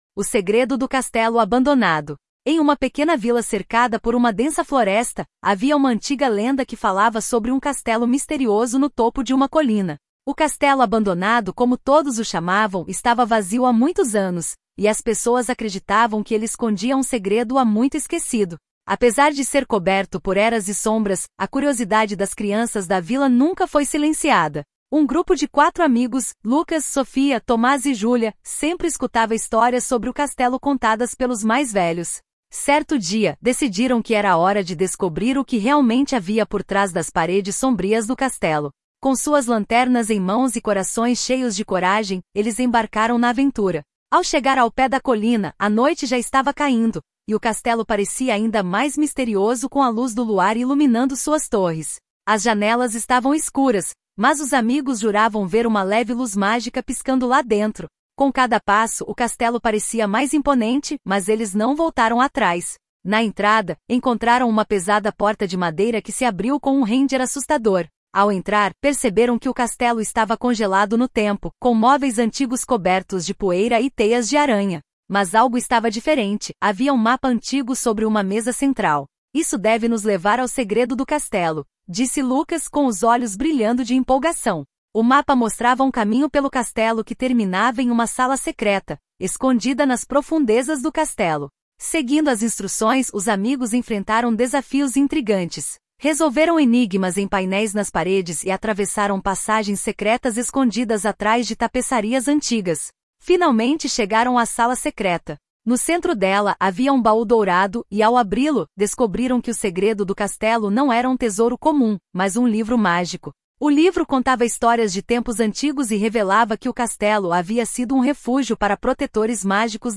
História infantil do castelo